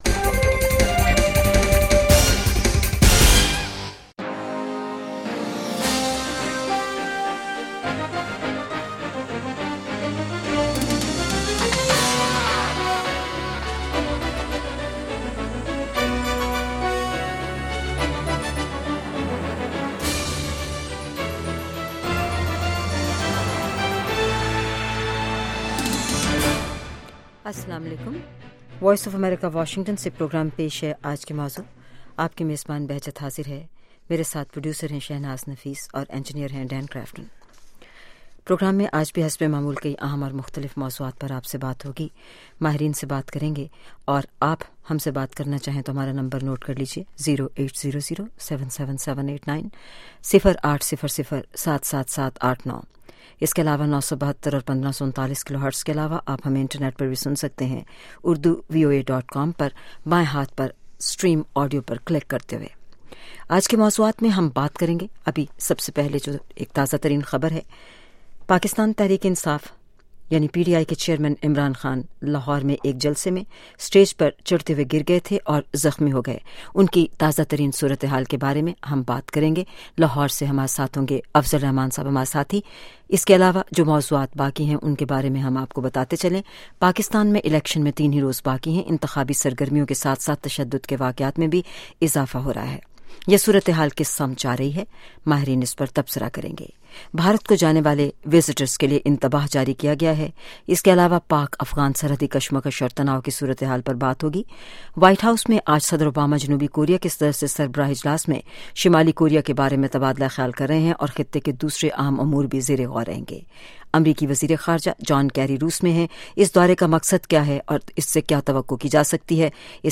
Sound Bites
Pakistan Election Special (Special Report) Political parties are trying hard to get the attention of the voters & preparations of just 3 days away elections are on full swing.